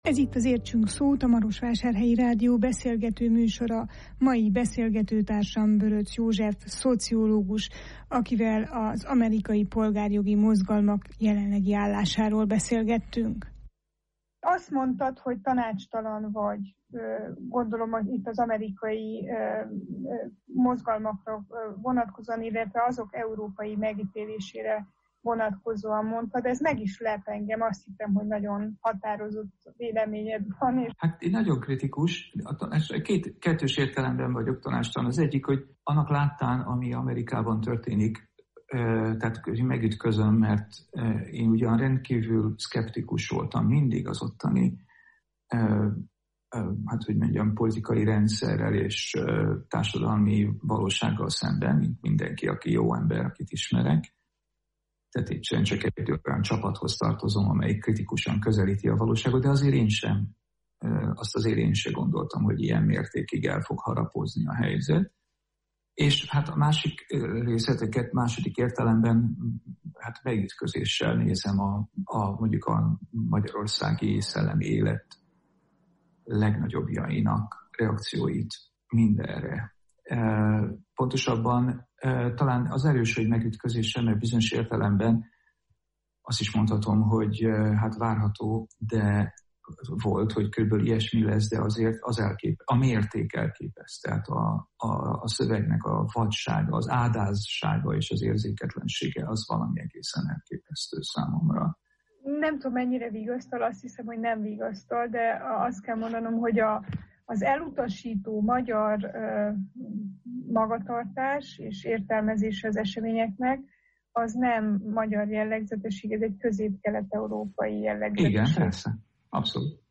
A huszadik század elején a kelet-európai bevándorlók sem számítottak “fehérnek” Amerikában. Mit jelent a fehérség, és mi kell ahhoz, hogy egy társadalmi csoport “kifehéredjen”? Nem a bőr színe számít! – figyelmeztet interjúalanyunk, akivel amerikai tapasztalatairól és jelenleg is zajló polgári engedetlenségi kezdeményezésekről beszélgettünk.